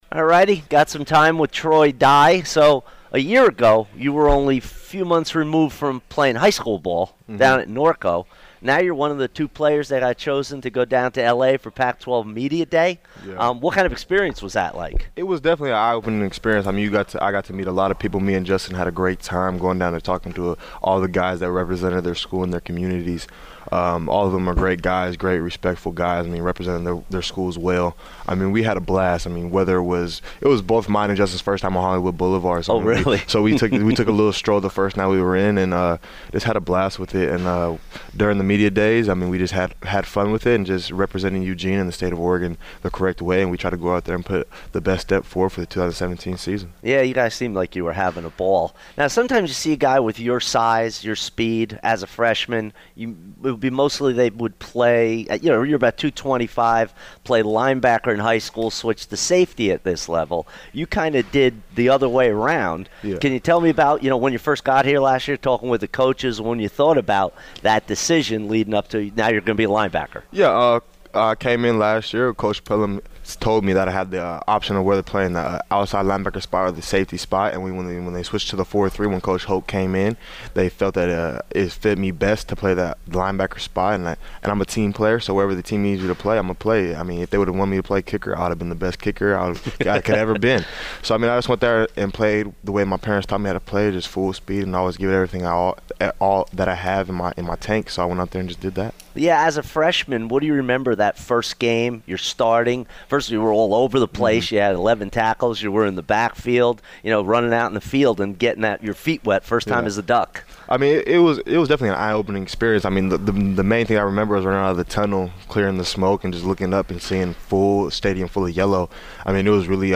Media Day 2017